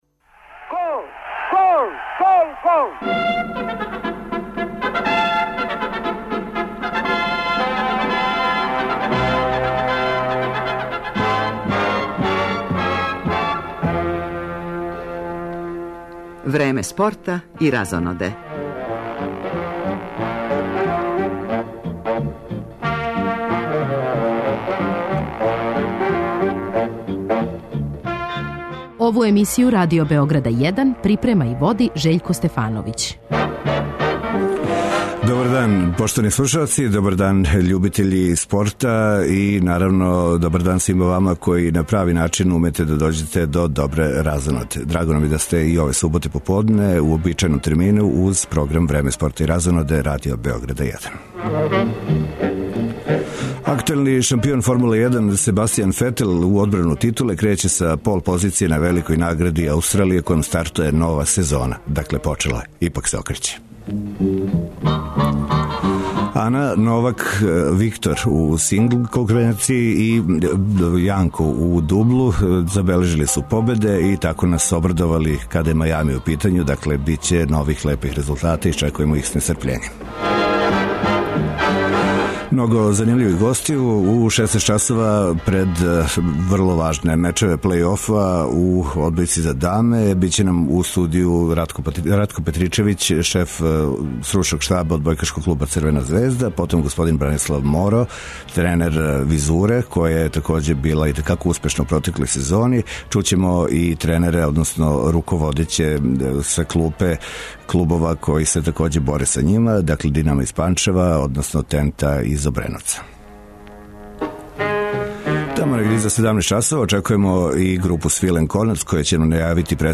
Породични радио магазин 'Време спорта и разоноде' и данас се обраћа свим генерацијама, па ћемо пратити дешавања на престижном тениском турниру у Мајамију, а биће речи и о актуелним утакмицама наше најбоље фудбалске селекције, као и младе репрезентације Србије.